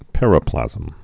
(pĕrə-plăzəm)